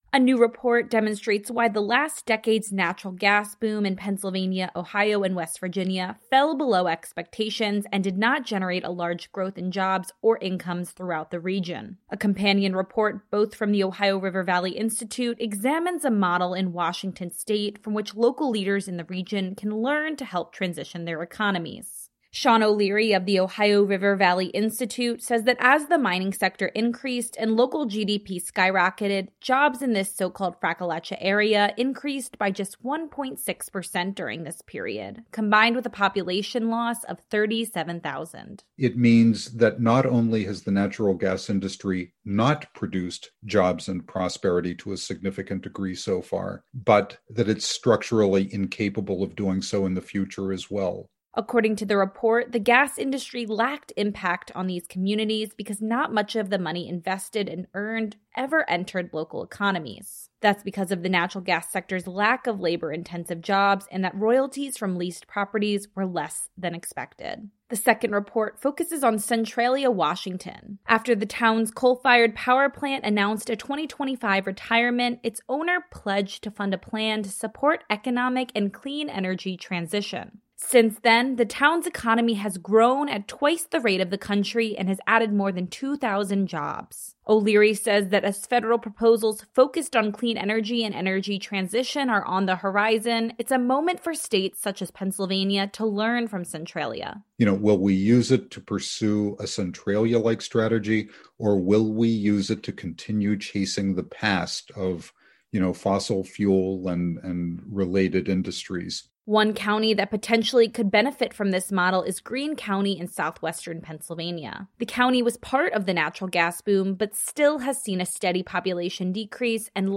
Protect Earth News Radio Reports: Oct-July, 2021 – Protect Earth News